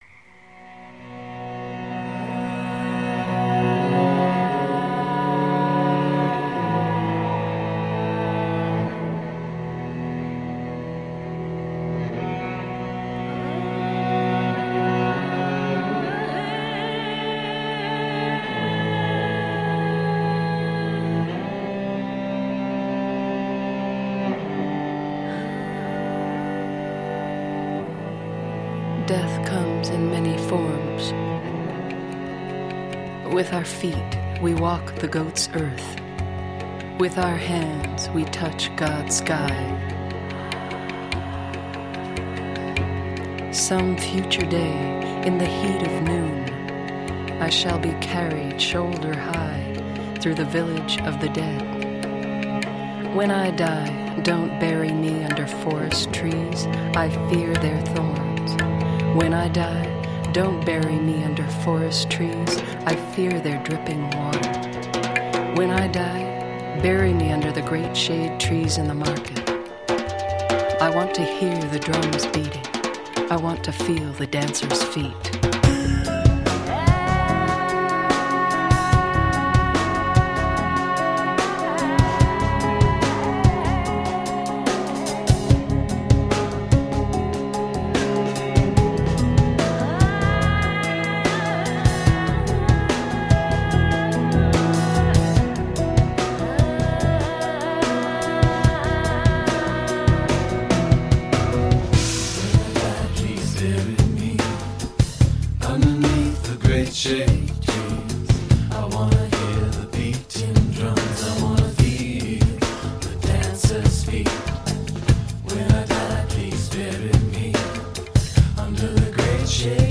Enchanting cello compositions.
Tagged as: New Age, Ambient, Cello, Ethereal